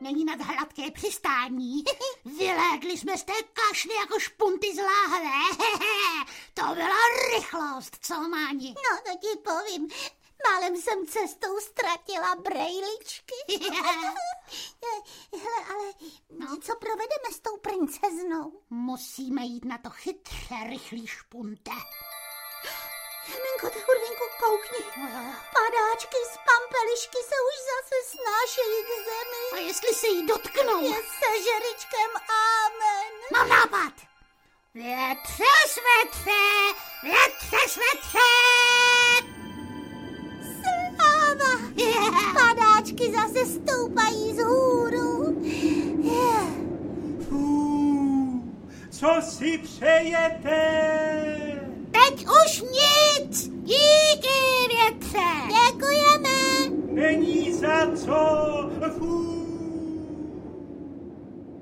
Titul je zvukovou verzí stejnojmenného představení z repertoáru Divadla S+H. A o čem je?
Audio kniha